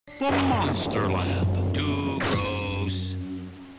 OF THE AWESOME "TOO GROSS" VOICE!